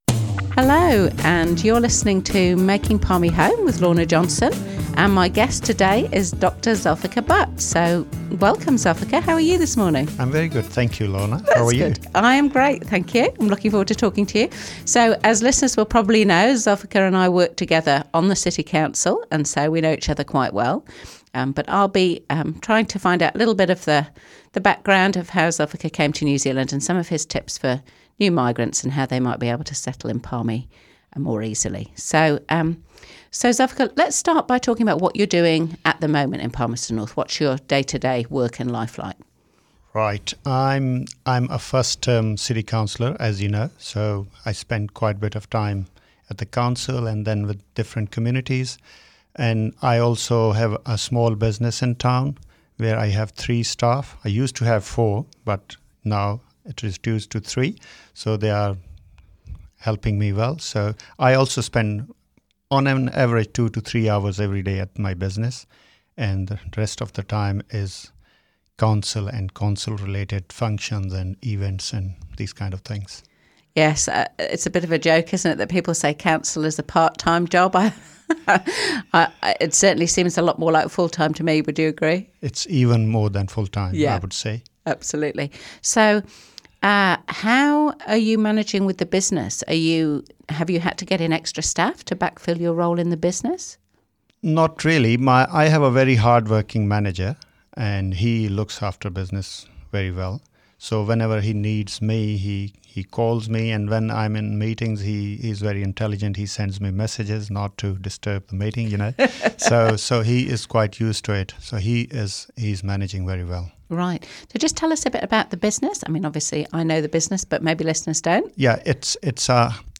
00:00 of 00:00 Add to a set Other Sets Description Comments Making Palmy Home - Ep 03 - Zulfiqar More Info → Description Palmerston North City Councillor Lorna Johnson hosts "Making Palmy Home", a Manawatū People's Radio series interviewing migrants about their journeys to Palmerston North. This episode features fellow City Councillor Dr. Zulfiqar Butt.
interview